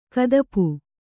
♪ kadapu